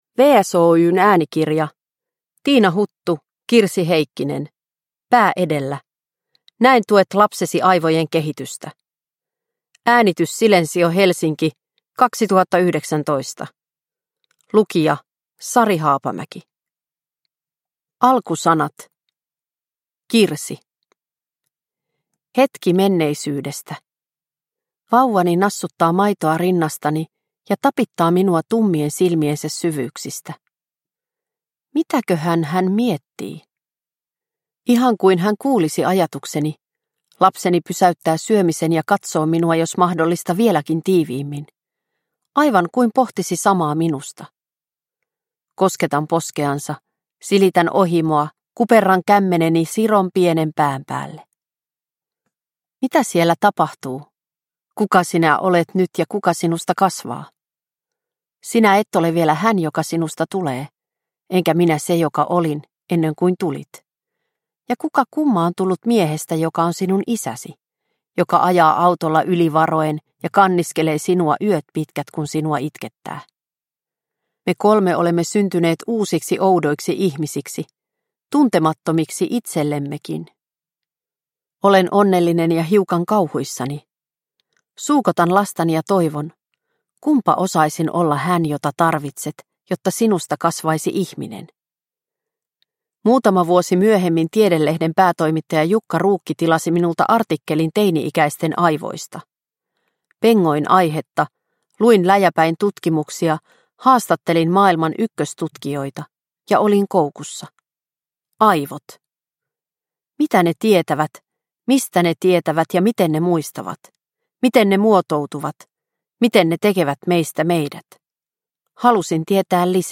Pää edellä – Ljudbok – Laddas ner